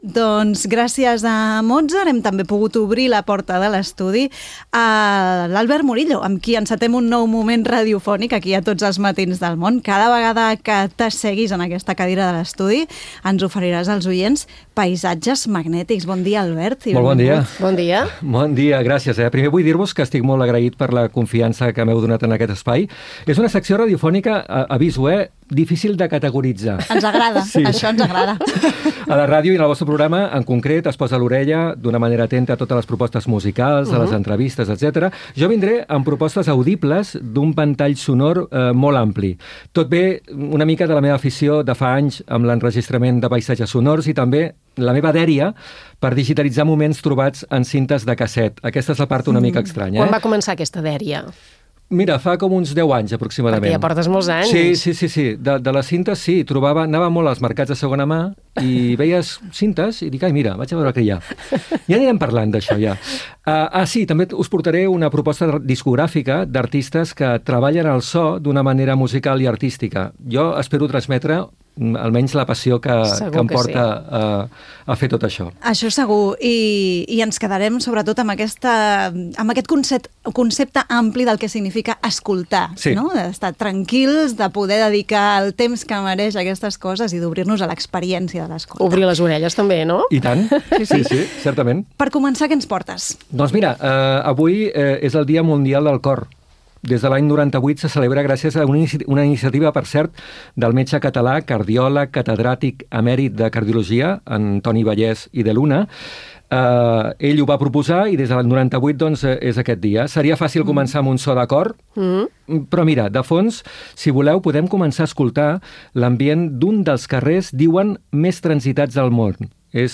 Gènere radiofònic Musical